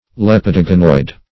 Search Result for " lepidoganoid" : The Collaborative International Dictionary of English v.0.48: Lepidoganoid \Lep`i*do*ga"noid\ (l[e^]p`[i^]*d[-o]*g[=a]"noid or -g[a^]n"oid), n. [Gr. lepi`s -i`dos, a scale + E. ganoid.]
lepidoganoid.mp3